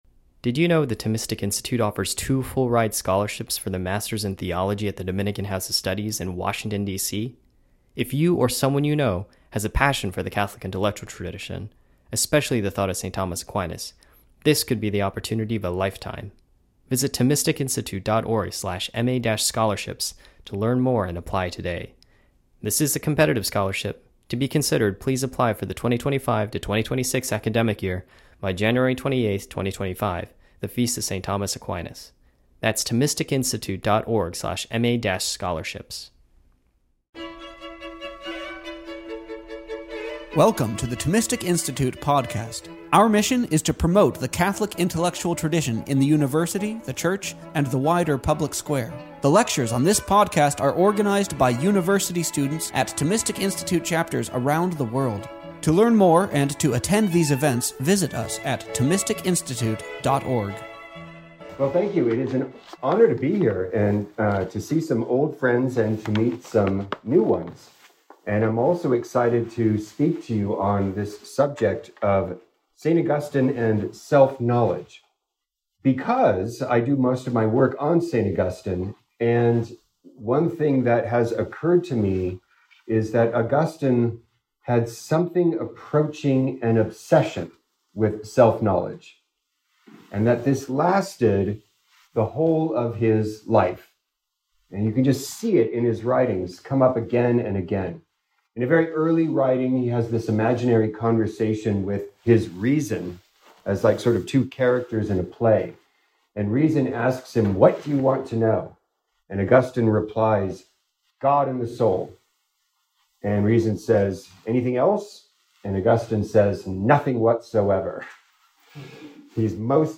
This lecture was given on September 19th, 2024, at University of Tulsa.